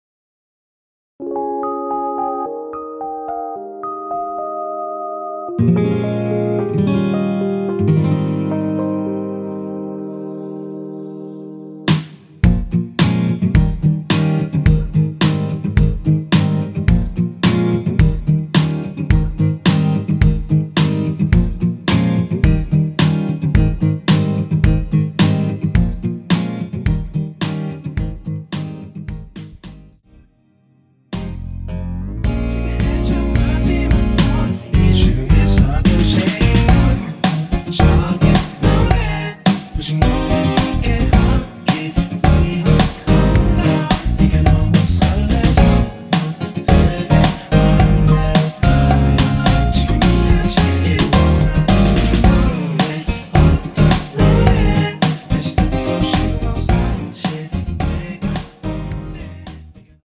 원키에서(-1)내린 코러스 포함된 MR입니다.
Ab
◈ 곡명 옆 (-1)은 반음 내림, (+1)은 반음 올림 입니다.
앞부분30초, 뒷부분30초씩 편집해서 올려 드리고 있습니다.